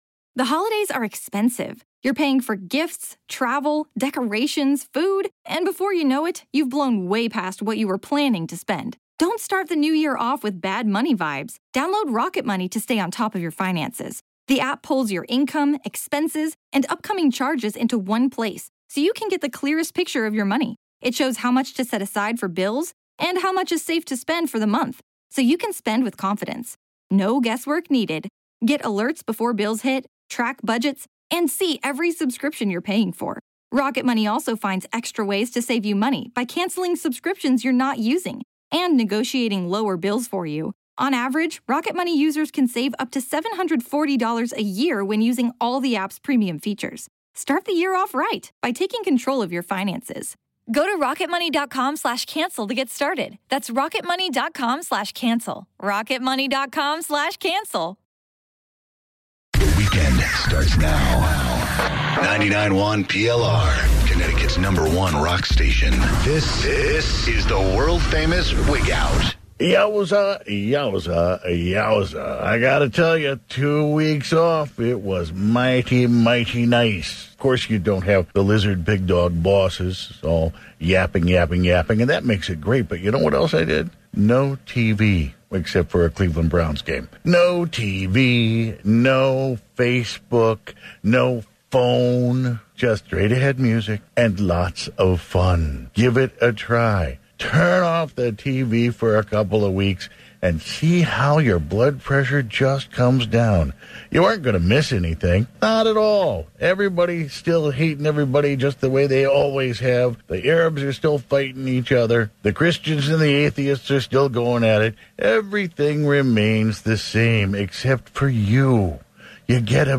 Live Wigout